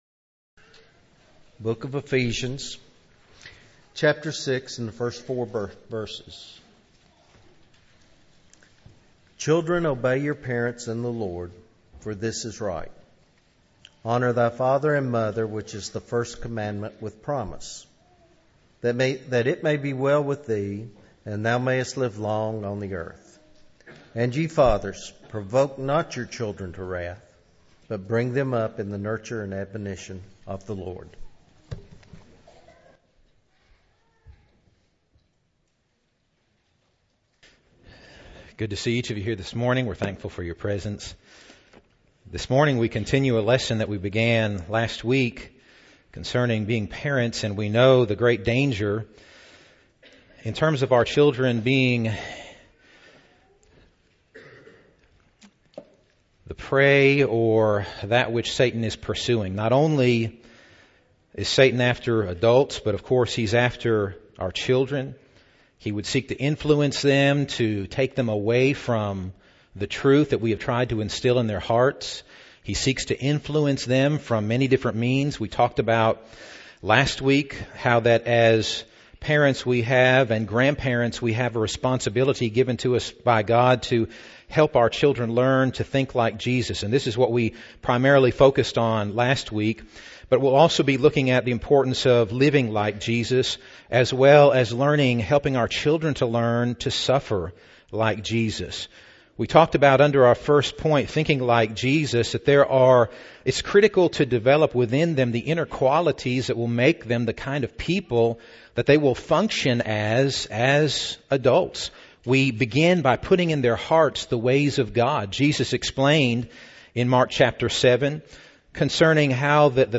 Eastside Sermons Service Type: Sunday Morning Download Files Bulletin « Moses